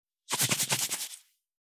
369,調味料固形物,カシャカシャ,サラサラ,パラパラ,ジャラジャラ,サッサッ,
効果音厨房/台所/レストラン/kitchen